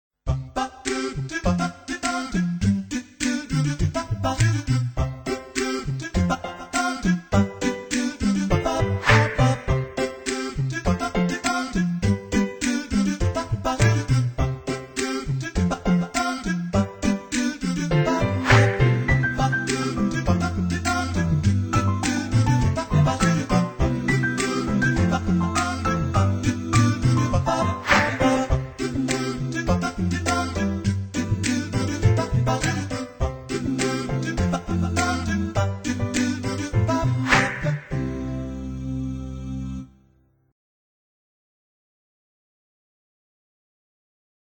纯人声无伴奏「阿卡贝拉」（A cappella）的音乐，最近在世界流行乐坛掀起了一阵的炫风。
原本冰冷的电子配乐，换上了温暖的人声，不同的表现，相同的好听